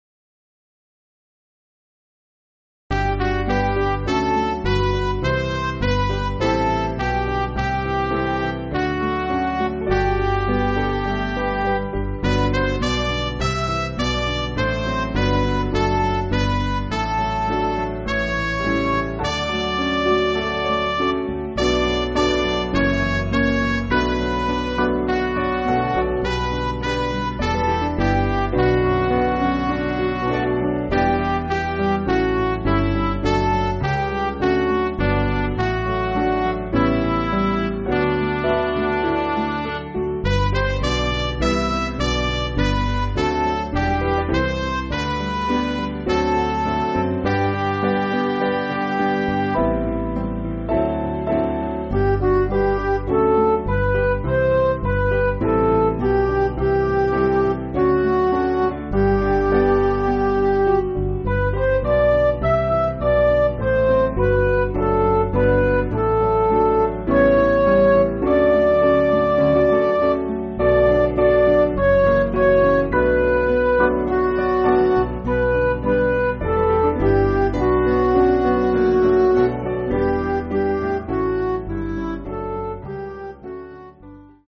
Piano & Instrumental
(CM)   6/G